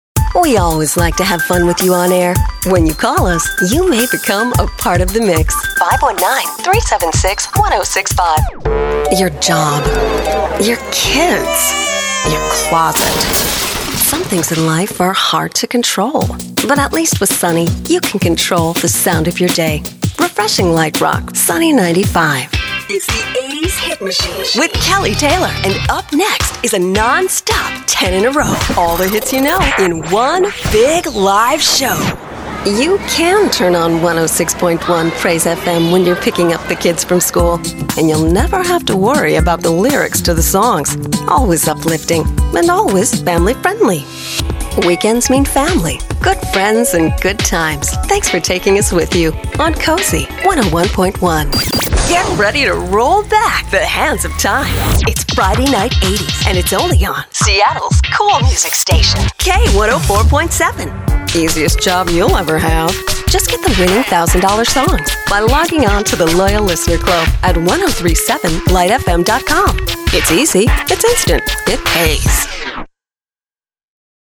middle west
Sprechprobe: Sonstiges (Muttersprache):